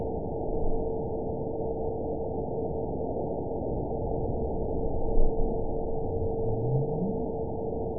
event 910583 date 01/22/22 time 11:20:15 GMT (3 years, 4 months ago) score 9.46 location TSS-AB02 detected by nrw target species NRW annotations +NRW Spectrogram: Frequency (kHz) vs. Time (s) audio not available .wav